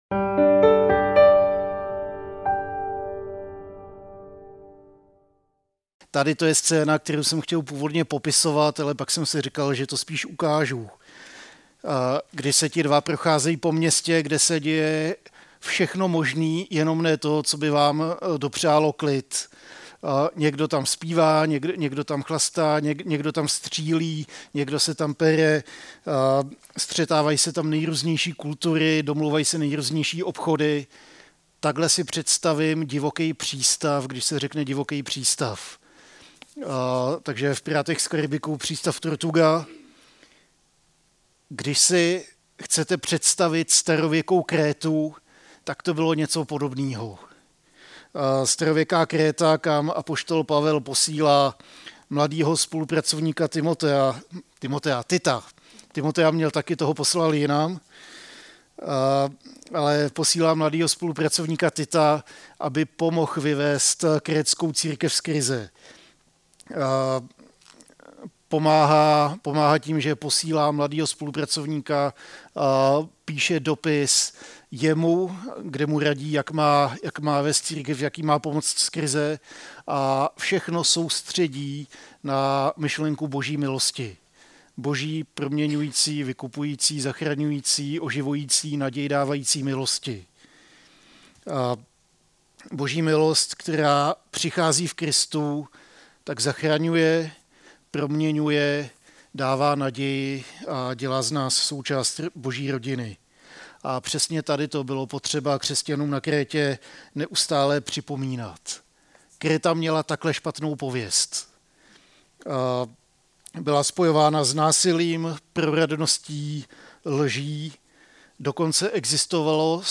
A kázání od série "Sofonjáš."